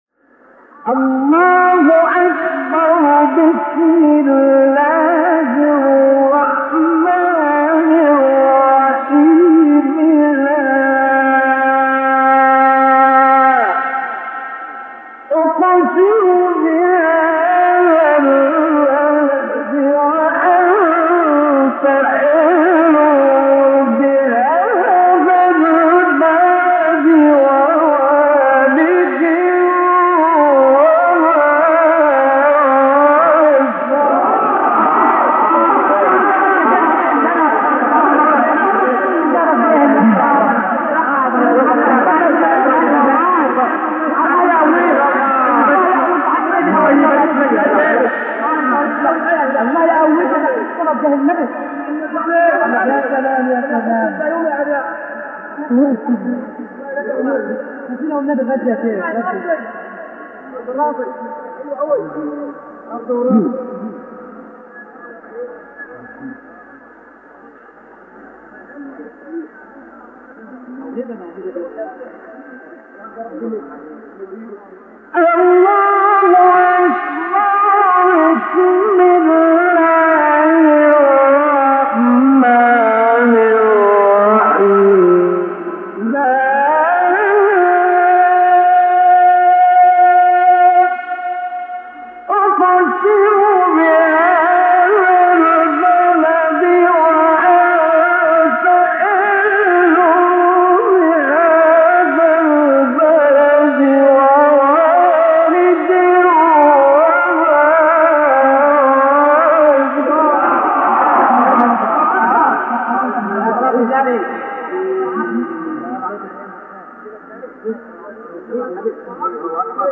آیات ابتدایی سوره بلد استاد مصطفی اسماعیل | نغمات قرآن | دانلود تلاوت قرآن